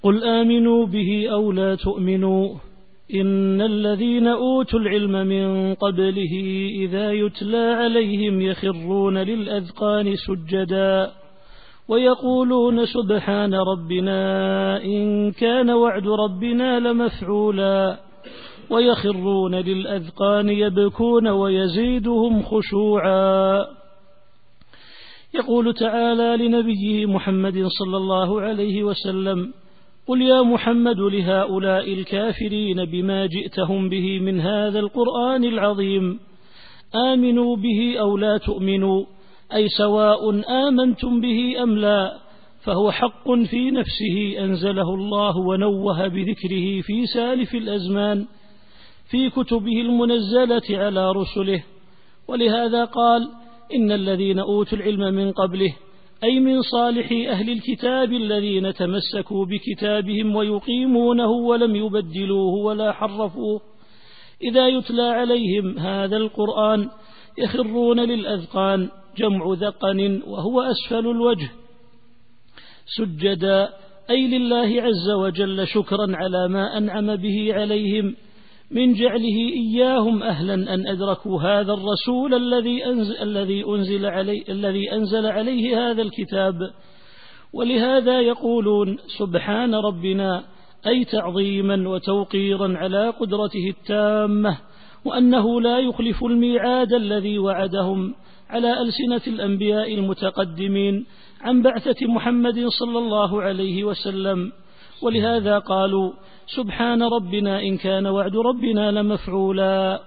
التفسير الصوتي [الإسراء / 107]